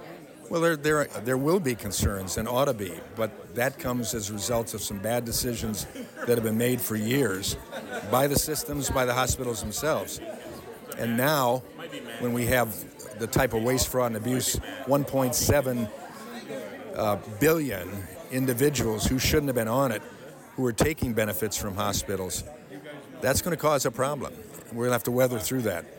COLDWATER, MI (WTVB)- During a town hall style meeting in Coldwater on Tuesday, one of the big topics of discussion was the potential for some rural hospitals being forced to shutter operations as a result of the recently passed Big Beautiful Bill and changes to Medicare and Medicaid.